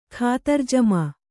♪ khātar jamā